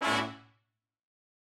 GS_HornStab-Bmin+9sus4.wav